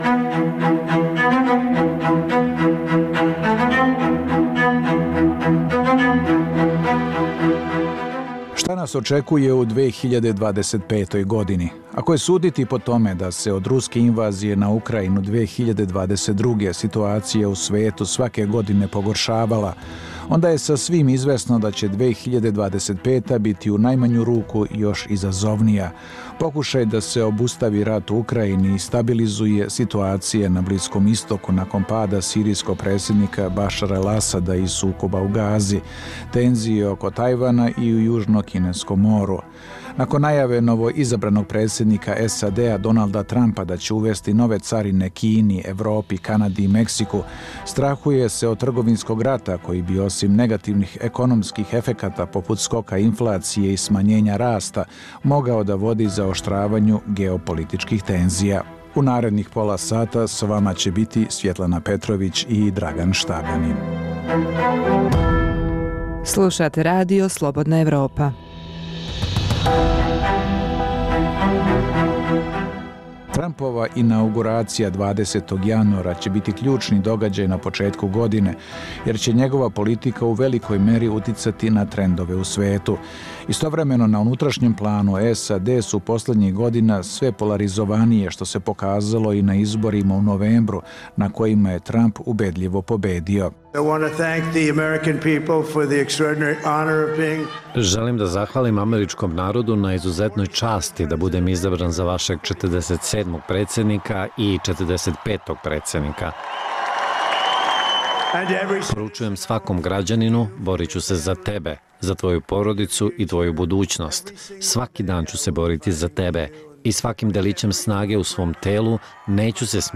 Dnevna informativna emisija Radija Slobodna Evropa o doga?ajima u regionu i u svijetu.